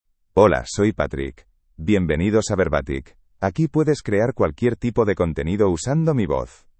MaleSpanish (Spain)
PatrickMale Spanish AI voice
Patrick is a male AI voice for Spanish (Spain).
Voice sample
Listen to Patrick's male Spanish voice.
Patrick delivers clear pronunciation with authentic Spain Spanish intonation, making your content sound professionally produced.